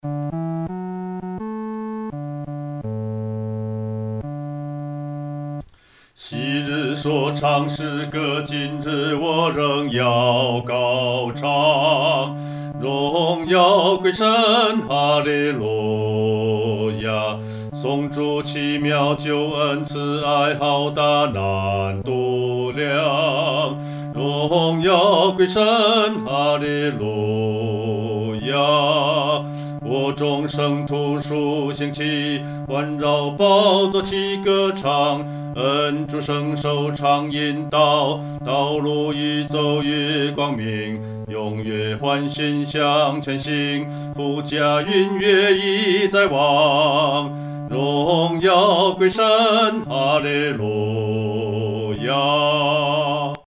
独唱（第四声）
这首赞美诗的曲调欢快，有进行曲的风味。
荣耀归神-独唱（第四声）.mp3